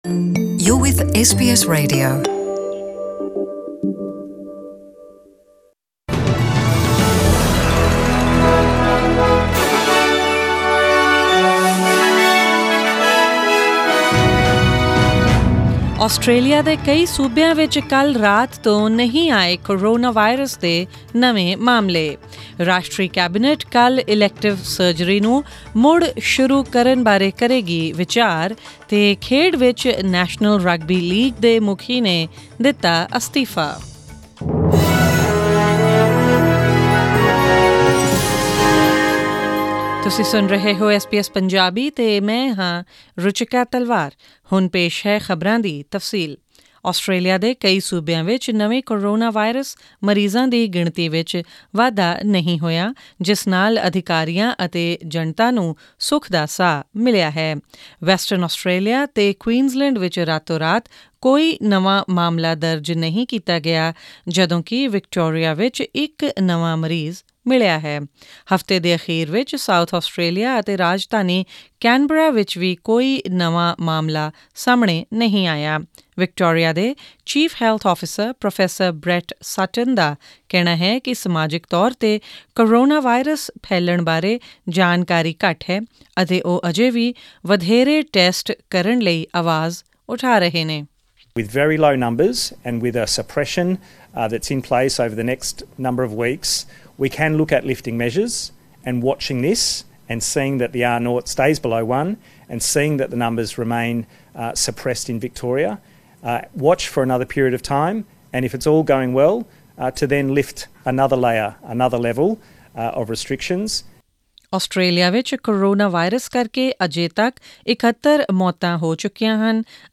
Australian News in Punjabi: 20 April 2020